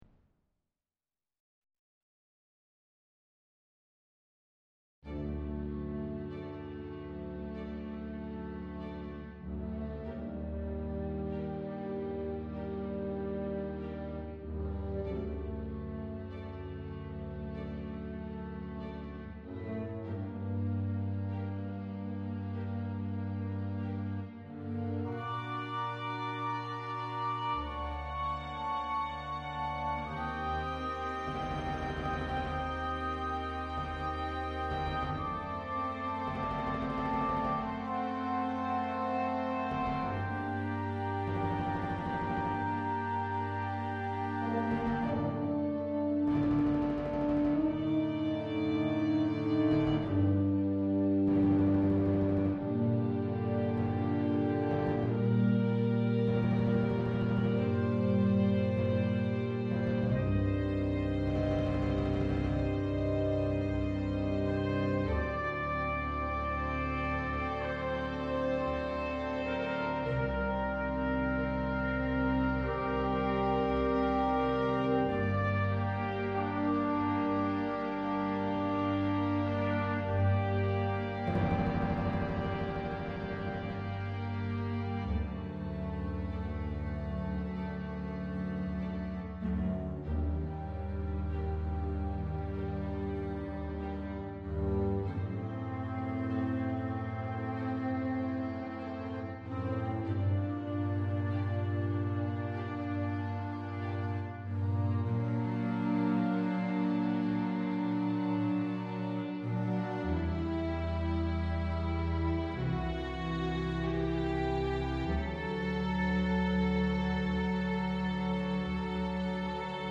je ne suis pas du tout spécialiste de ce genre de musique; mais un certain déséquilibre de mix me semble évident;
par exemple les choeurs sont beaucoup trop forts à des moments et certains sons de synthé sont aussi trop forts
les percussions sont presque noyé et très lointain
et si c'est toi qui gère pour l'instant la reverbe, je trouve qu'il y a un poil de trop sur les tambours et peut-être pas assez sur les voix et choeurs (enfin c'est un détail)